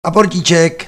Snad to umožní čtenáři udělat si lepší představu, co máme na mysli, mluvíme-li o tom, že povely by měly být zřetelné znělé a s výraznou intonací.